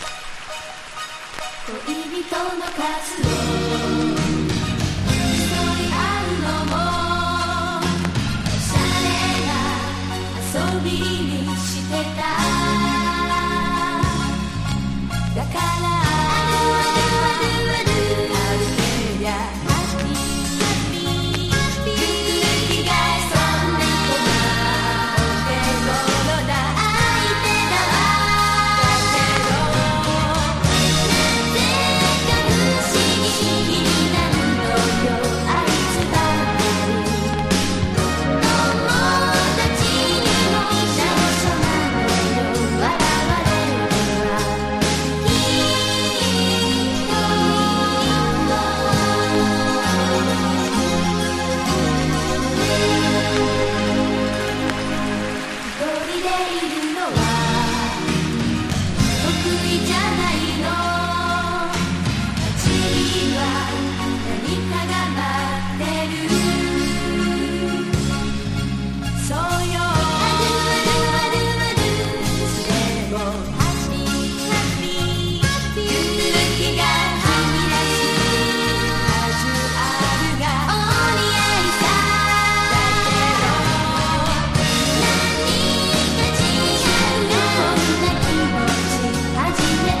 和モノ / ポピュラー# 70-80’S アイドル
• 盤面 : EX+ (美品) キズやダメージが無く音質も良好